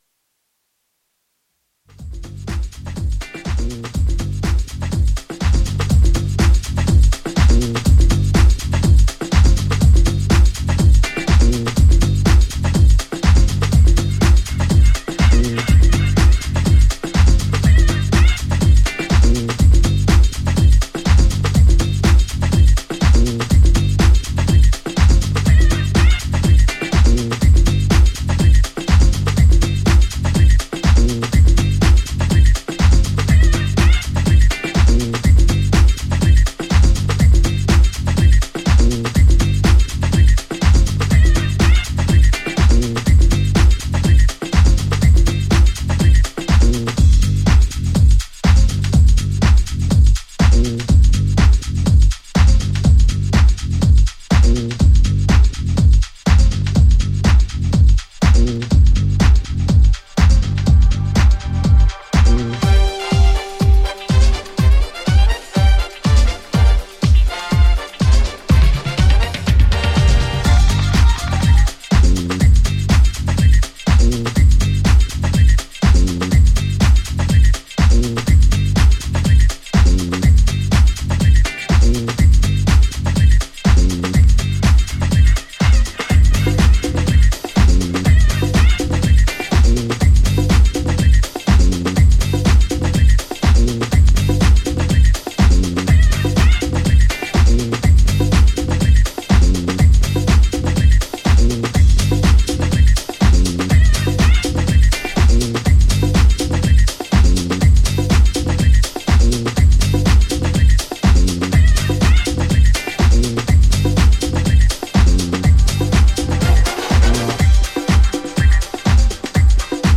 ジャンル(スタイル) DEEP HOUSE / FUNKY HOUSE